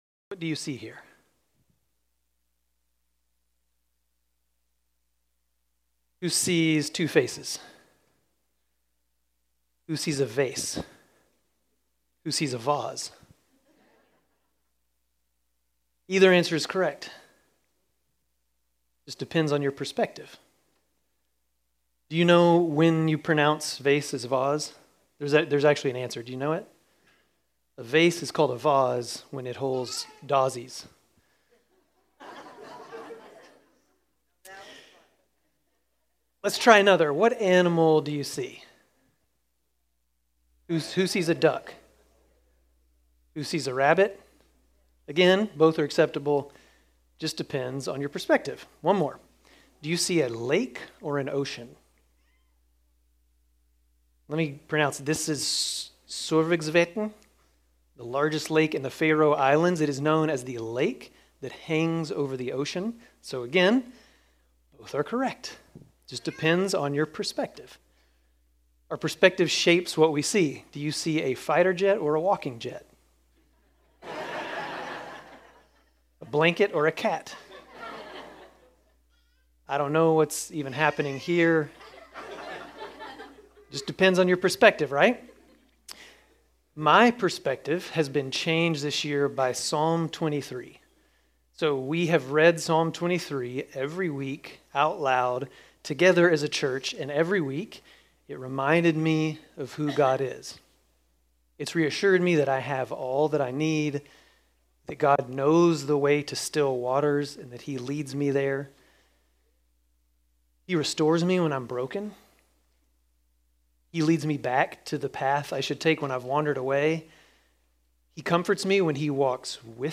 Grace Community Church Dover Campus Sermons 8_10 Dover Campus Aug 11 2025 | 00:26:55 1x 00:00 / 00:26:55 Subscribe Share RSS Feed Share Link Embed